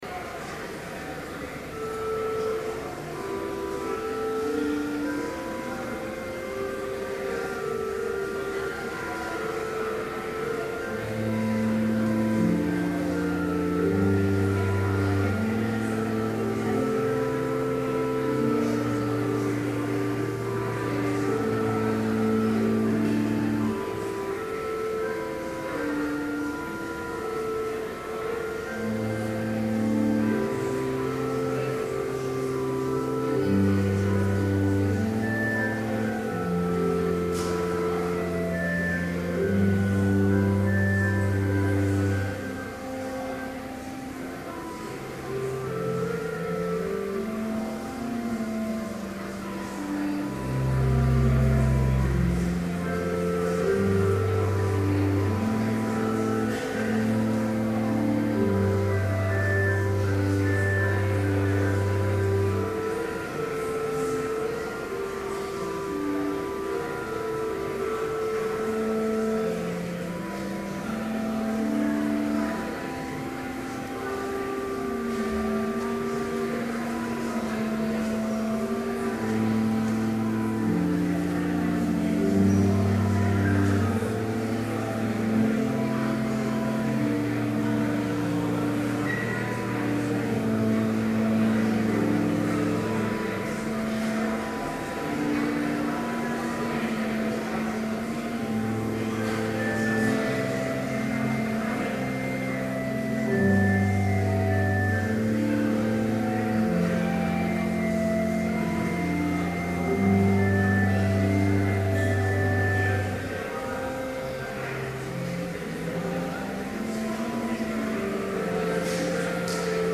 Complete service audio for Chapel - March 21, 2012